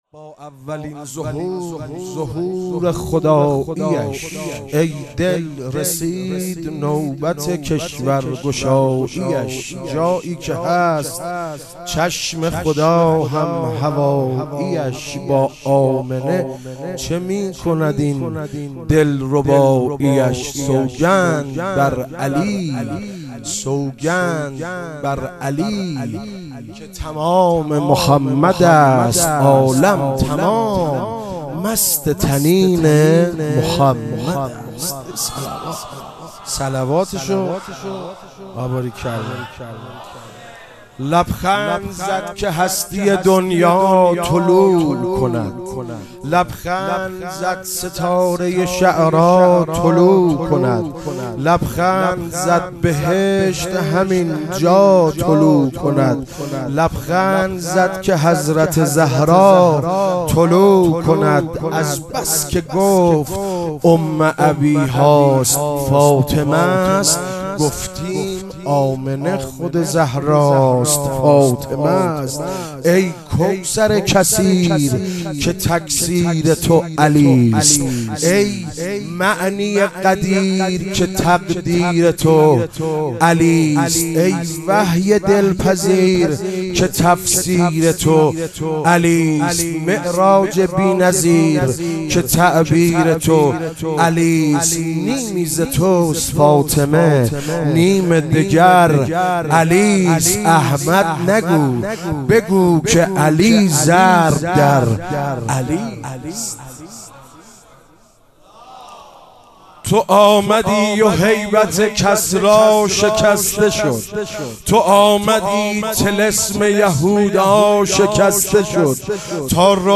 میلاد حضرت رسول اکرم (صلی الله علیه و آله) و امام صادق (علیه السلام)